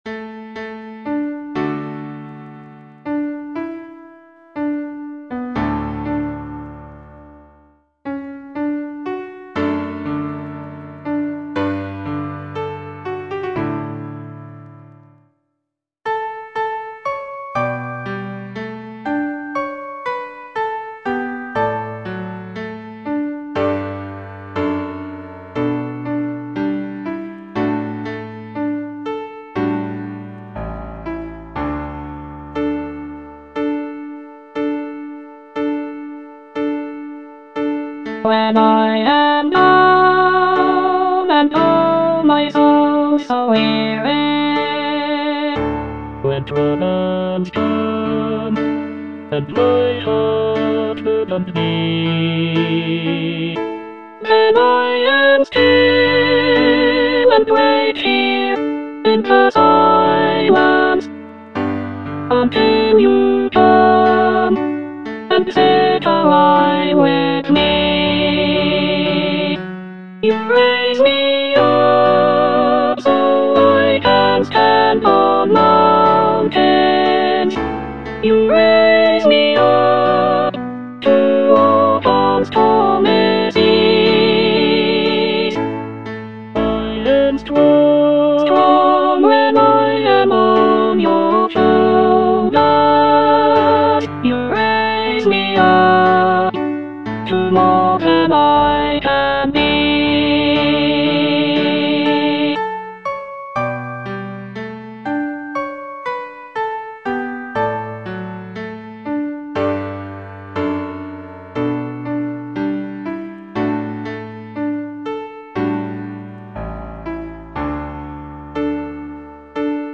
Soprano (Emphasised voice and other voices)